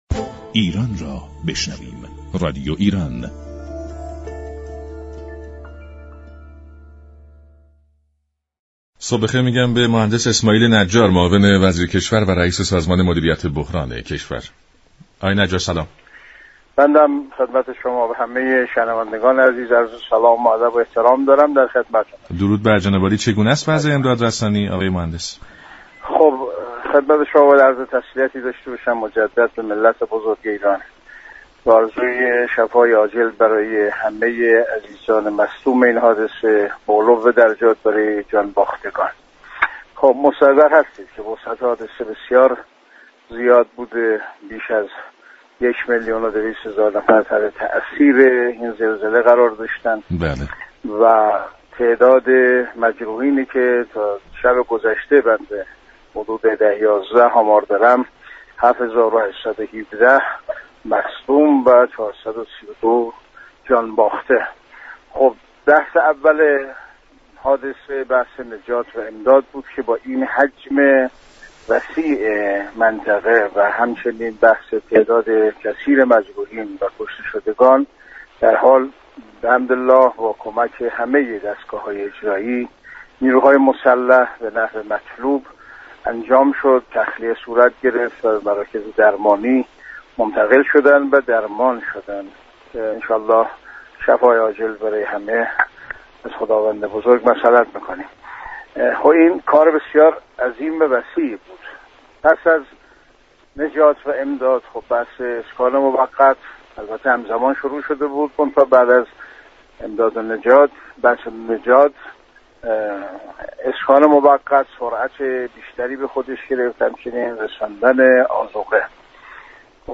اسماعیل نجار معاون وزیر كشور در گفت و گو با رادیو ایران اظهار امیدواری كرد كه عملیات امداد رسانی لحظه به لحظه با شتاب بیشتری انجام گیرد.